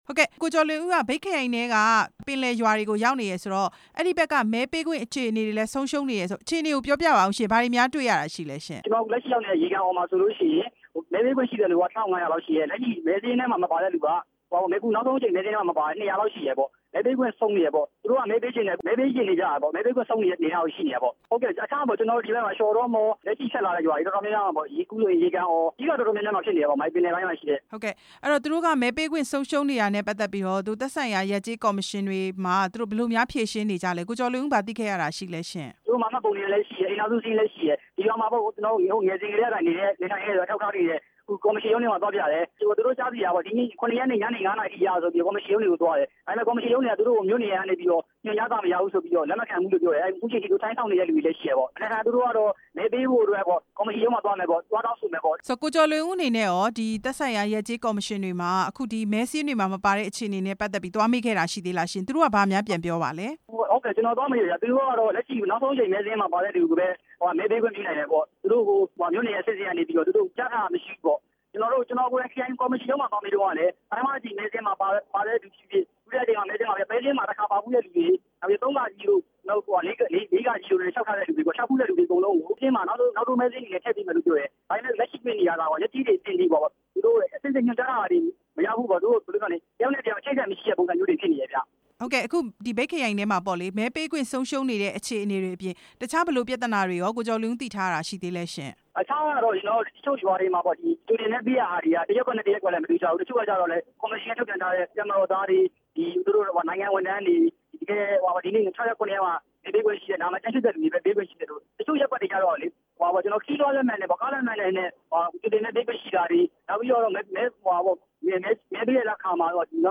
မြိတ်ခရိုင် ကျွန်းစုမြို့နယ်ထဲက မဲပေးခွင့်ဆုံးရှုံးနေကြတဲ့အခြေအနေ မေးမြန်းချက်